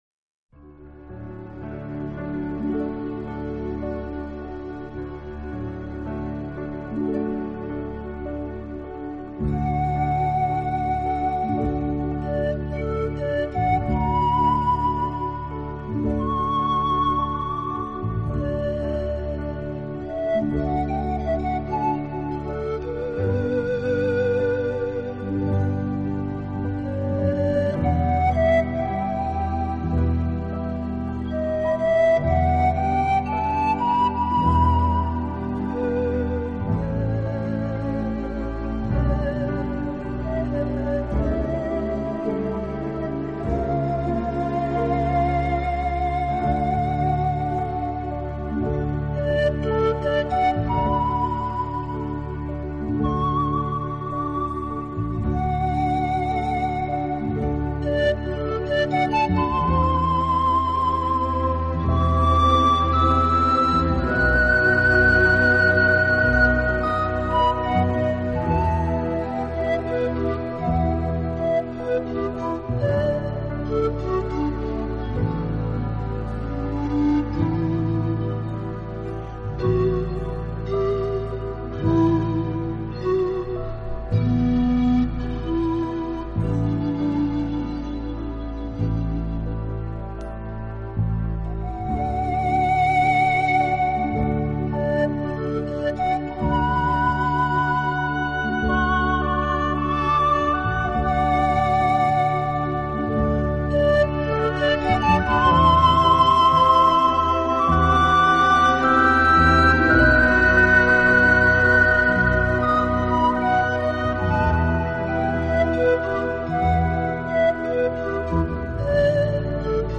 超广角音场的空间感演绎，大自然一尘不染的精华，仿佛让你远离凡尘嚣暄，